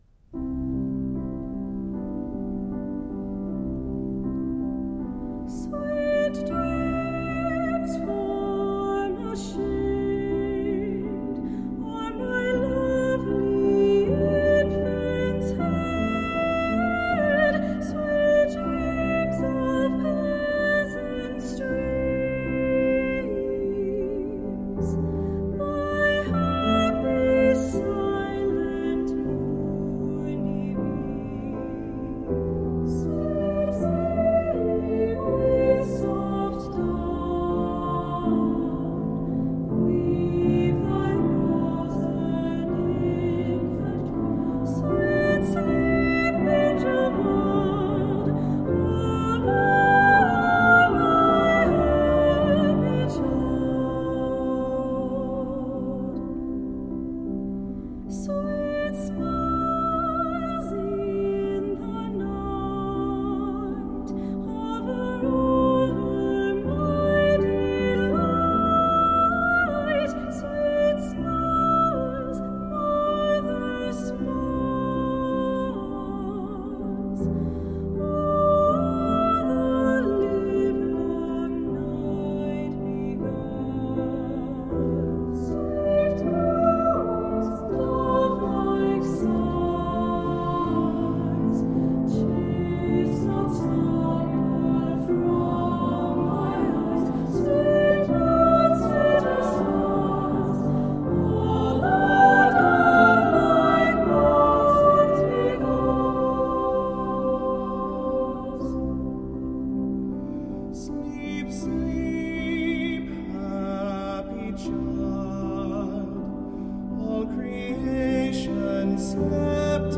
SSA and Piano
Voicing: "SAA","Soprano Solo, Alto Solo"